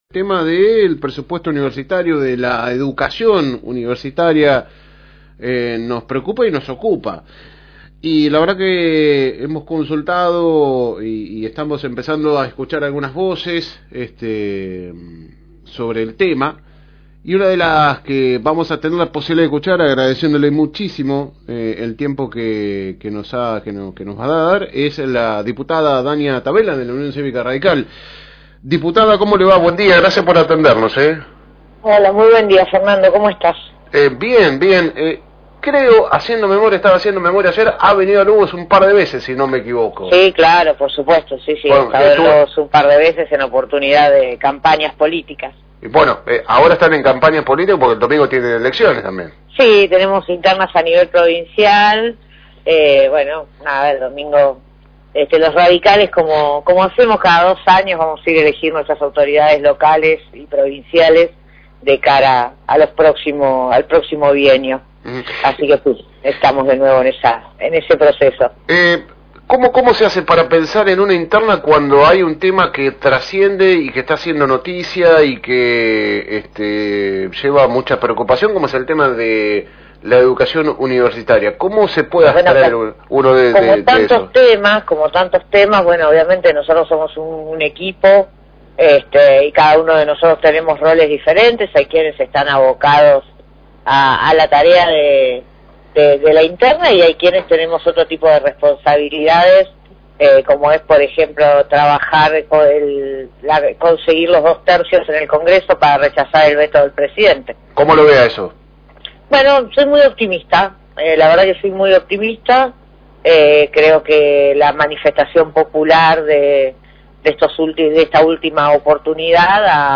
AUDIO – Entrevista a con la Diputada Nacional de la UCR Danya Tavela – FM Reencuentro